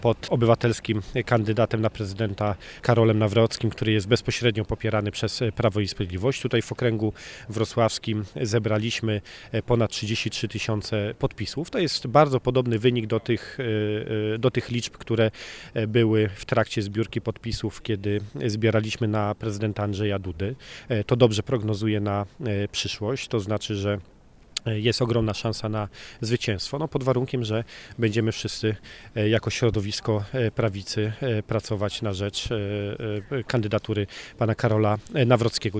Liczba podpisów zebranych pod kandydaturą Karola Nawrockiego napawa optymizmem przed wyborami – mówi Paweł Hreniak, poseł na Sejm.